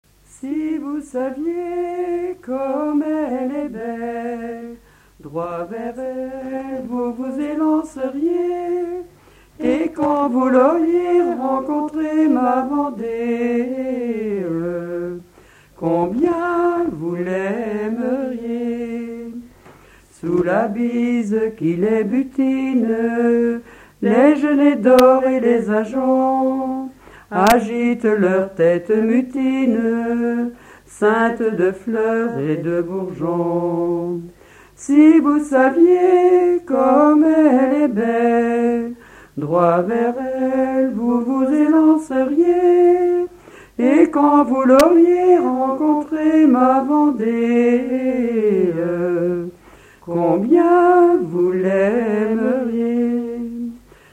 Localisation Sainte-Pexine
Pièce musicale inédite